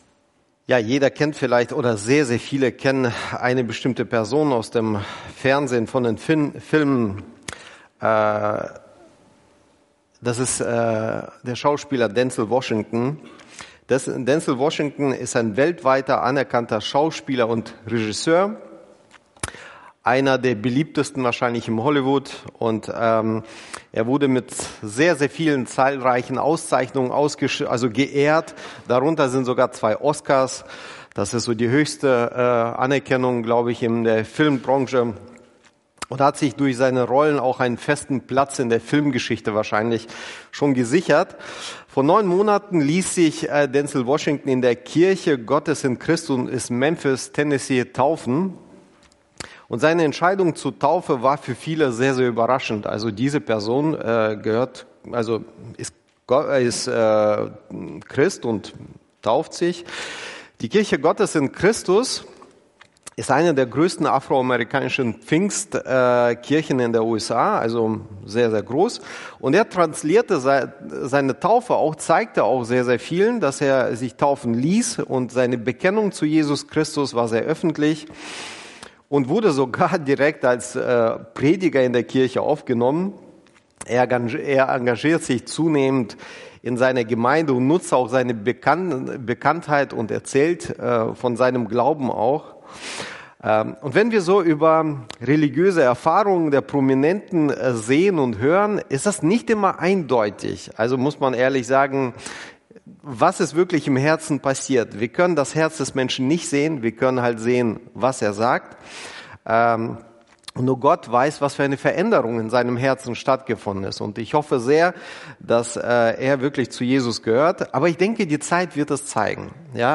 Verknüpfte Predigten